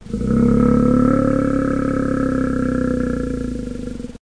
bear-groan-2.ogg